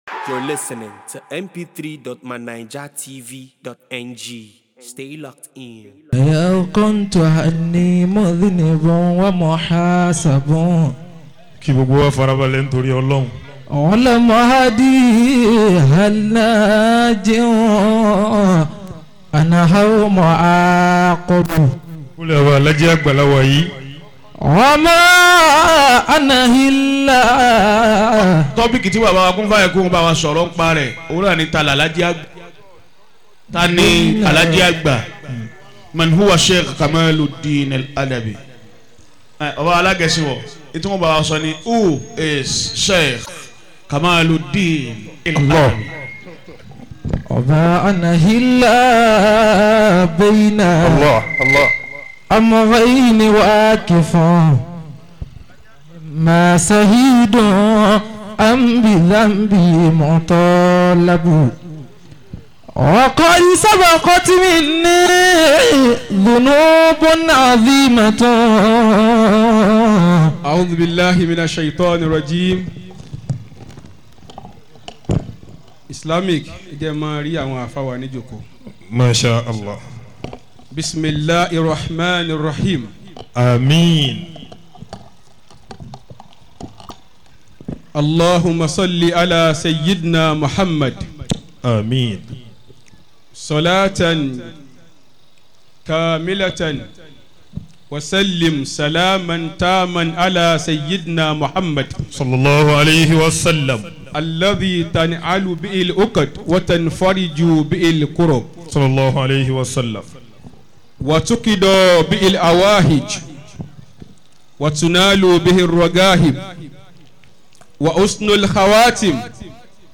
Lecture
at Sheikh Olohunwa Ayara Islamic Foundation Annual Mawlid Nabiyy